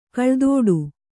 ♪ kaḷdōḍu